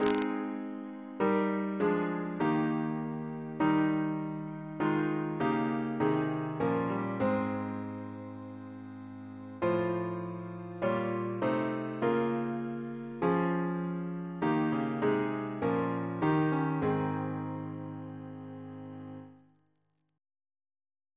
Double chant in A♭ Composer: F. A. Gore Ouseley (1825-1889) Reference psalters: ACB: 97; ACP: 354; CWP: 32; PP/SNCB: 82